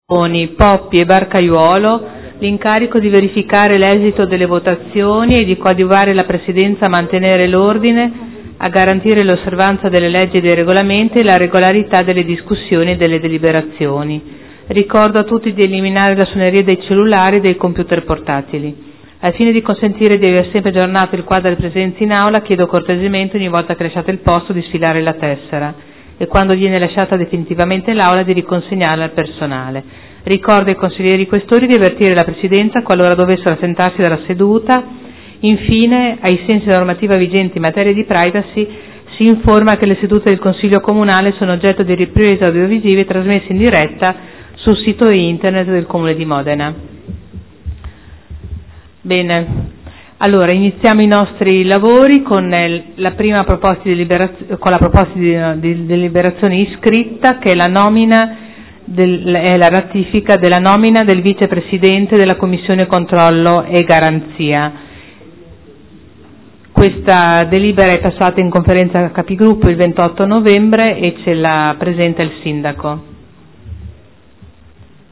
Il Presidente Caterina Liotti apre i lavori del Consiglio.